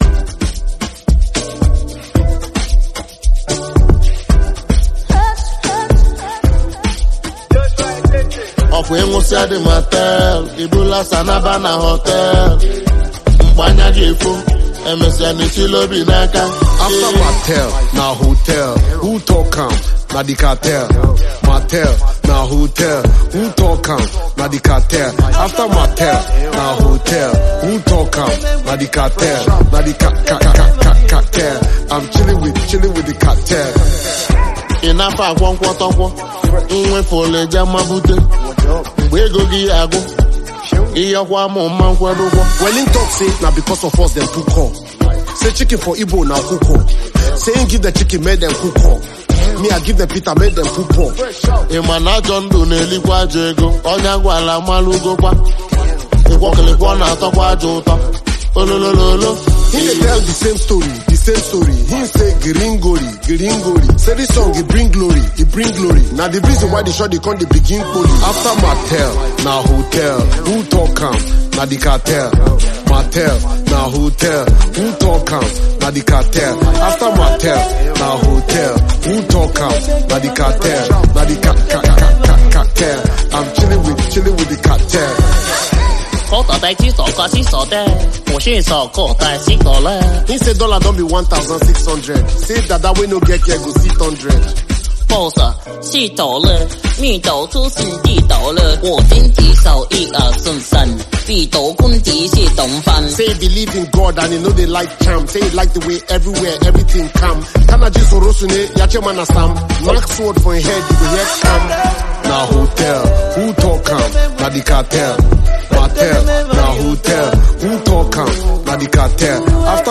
The remix combines traditional and contemporary elements